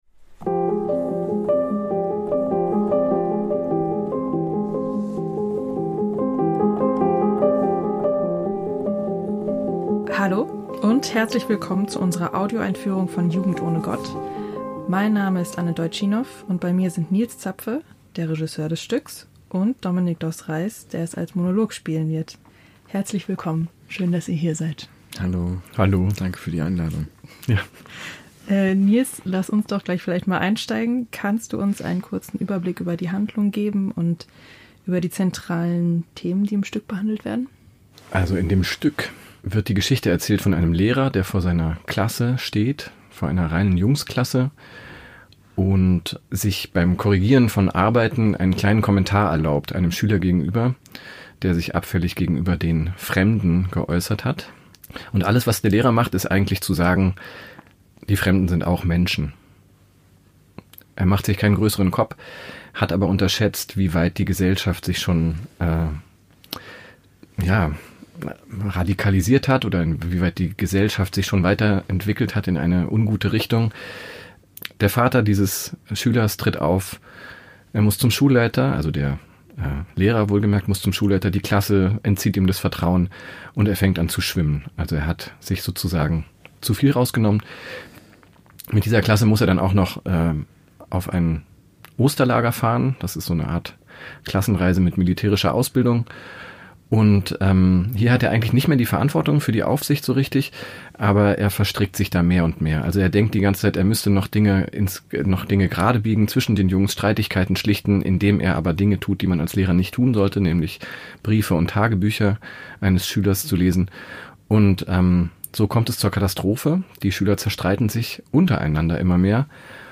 Erfahren Sie mehr über neue Inszenierungen aus dem Schauspielhaus Bochum in der neuen Episode der Talkreihe und Audioeinführung mit Künstler*innen und Dramaturg*innen der Produktion.